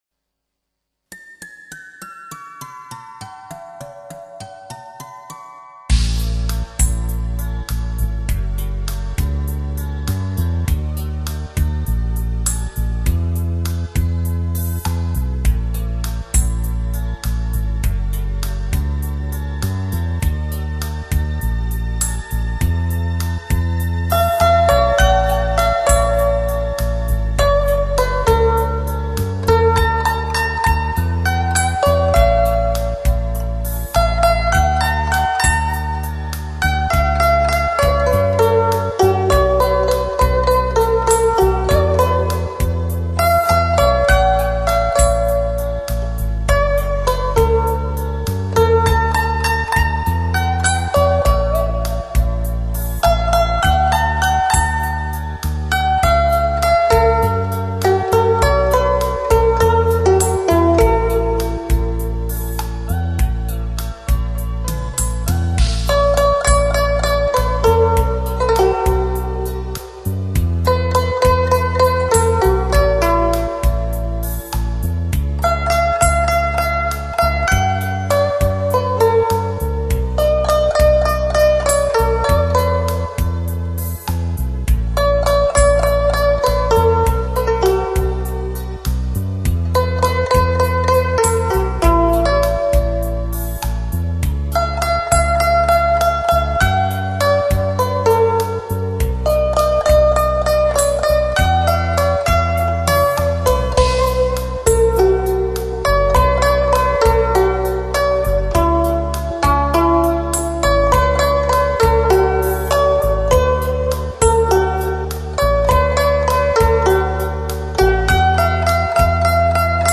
light]纯音乐
悠扬典雅丝竹之声完美民族经典，现代经典流行曲目
浓郁的韵味 只留下筝情永远